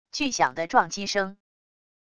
巨响的撞击声wav音频